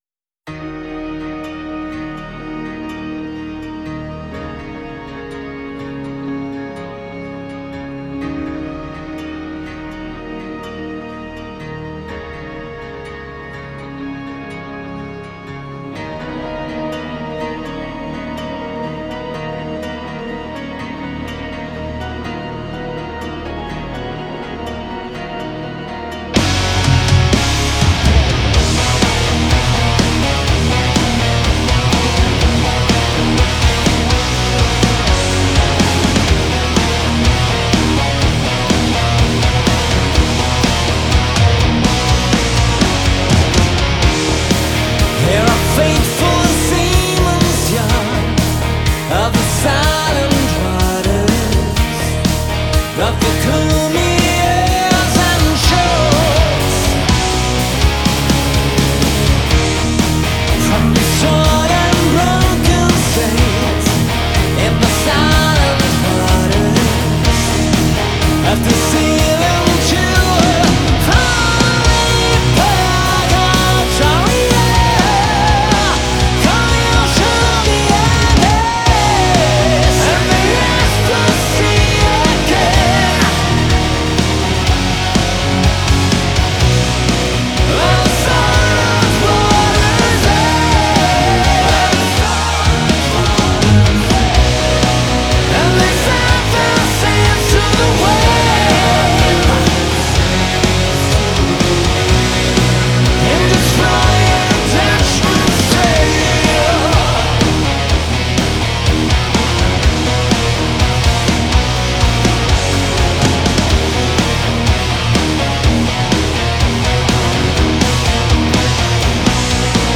Genre: Melodic Hard Rock